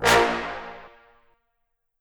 Fat_Horn_1.wav